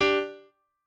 piano2_14.ogg